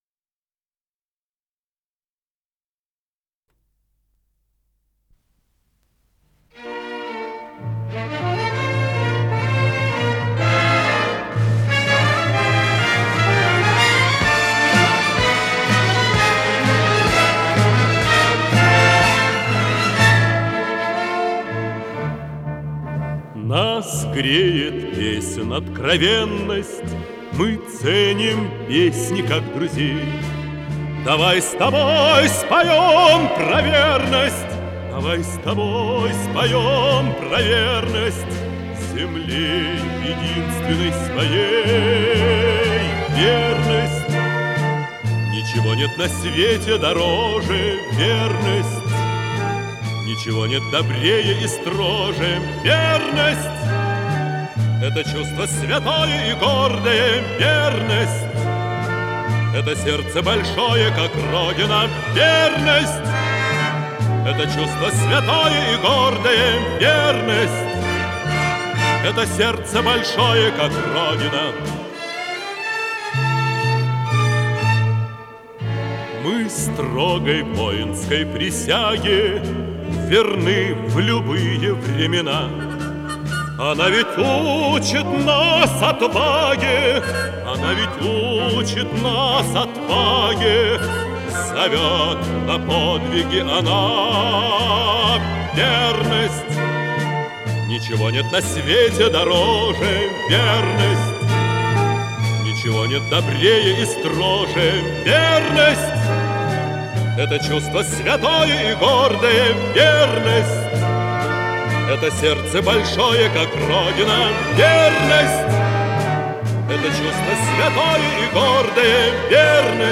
с профессиональной магнитной ленты
баритон
ВариантДубль моно